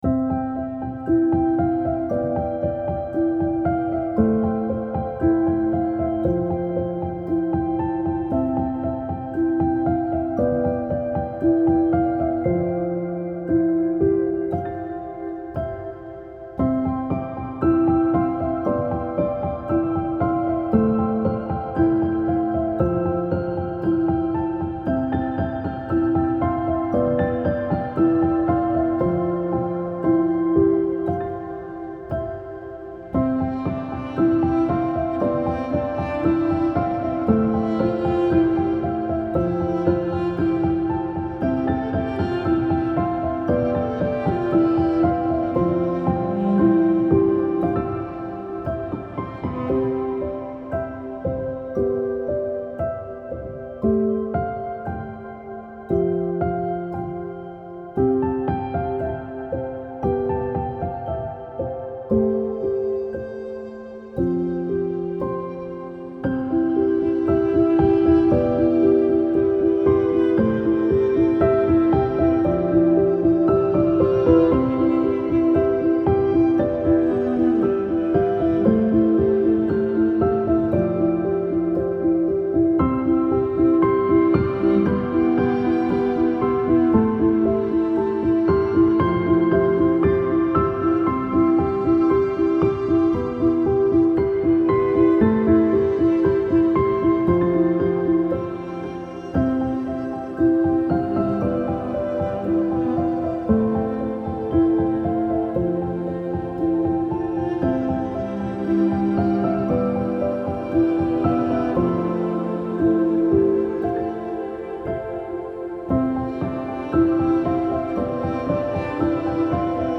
سبک آرامش بخش , پیانو , موسیقی بی کلام